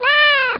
meow.mp3